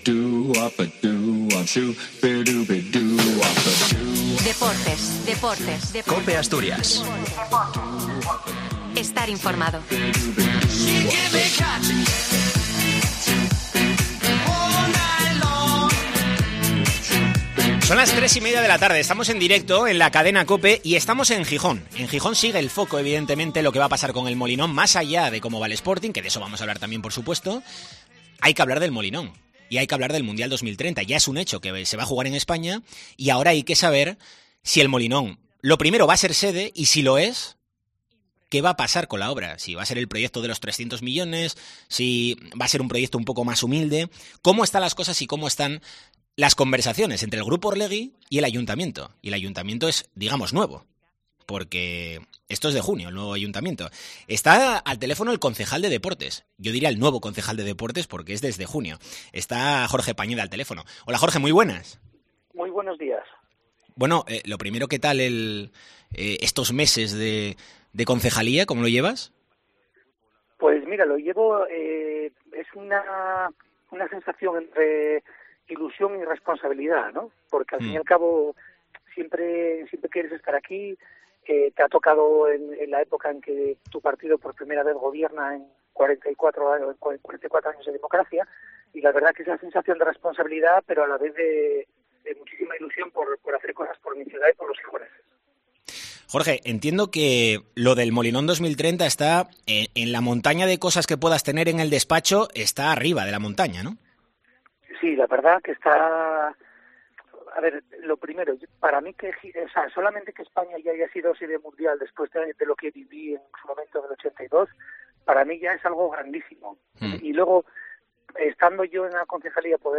Entrevista a Jorge Pañeda, concejal de Deportes del Ayuntamiento de Gijón